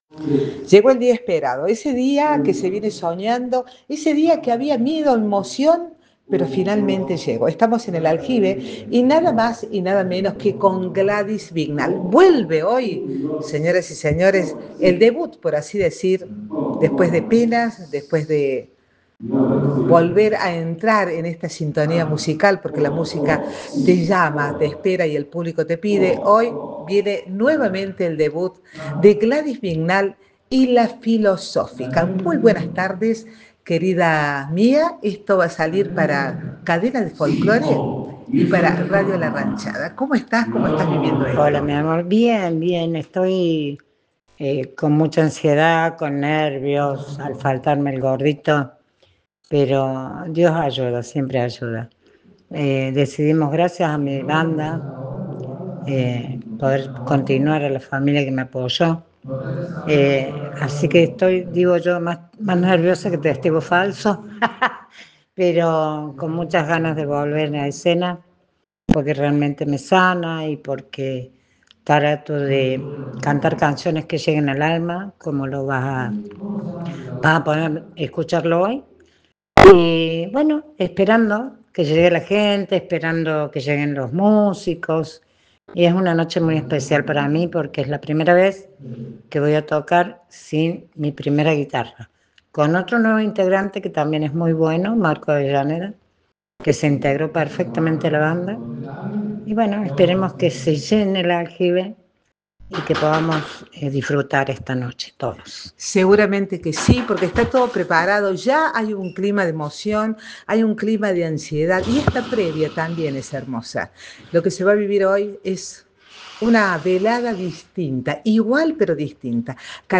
se presento este sábado en una jornada musical